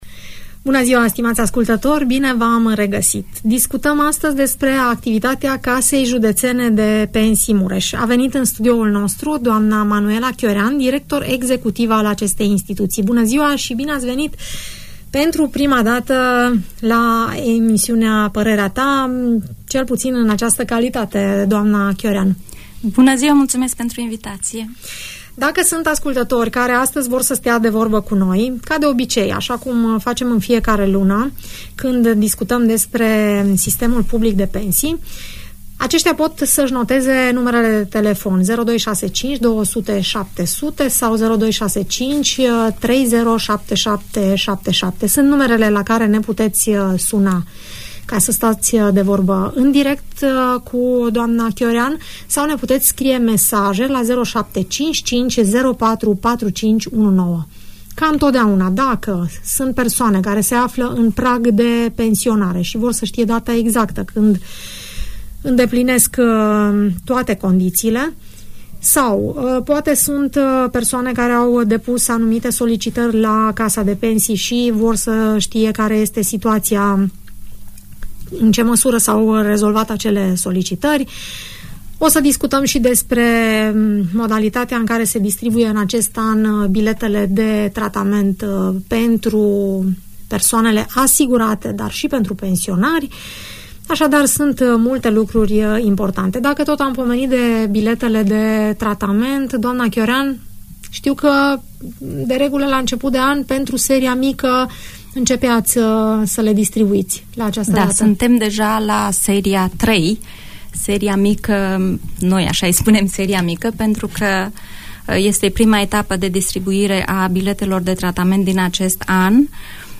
Întrebările pensionarilor, dar și ale celor care se află în pragul retragerii din activitate, își găsesc răspunsul în emisiunea „Părerea ta”. Urmărește dialogul